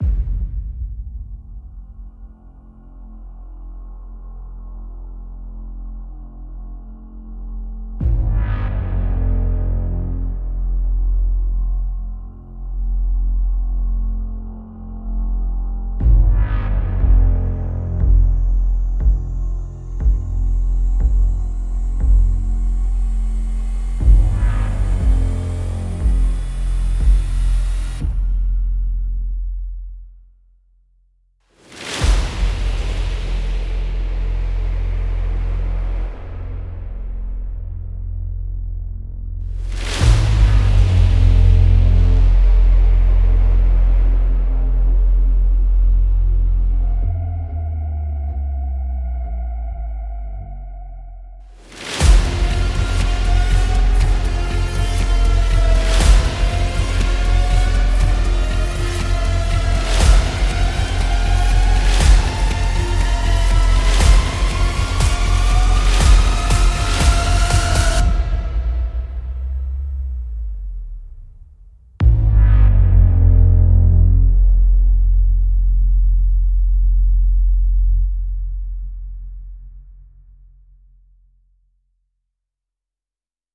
无限低音引擎
- 声音类别：贝斯，电影，电子，低菲，降速，有机，声音设计